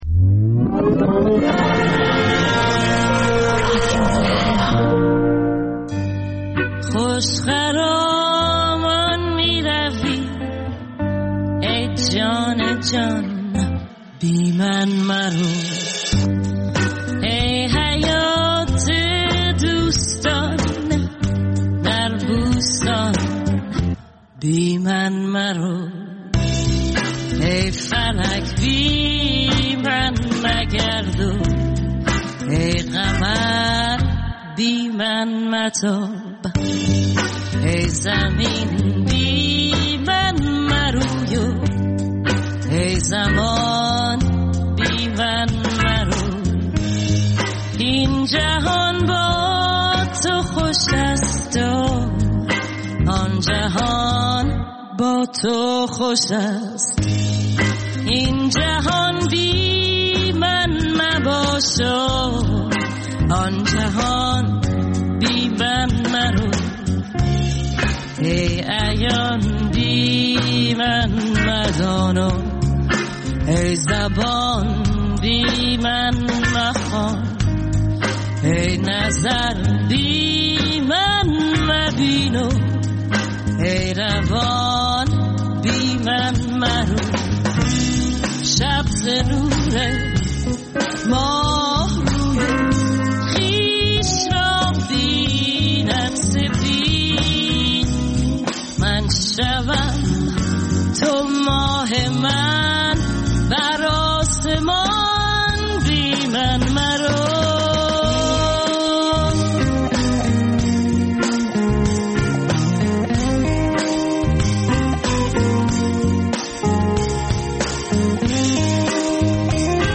دقایقی با موسیقی جز سول و بلوز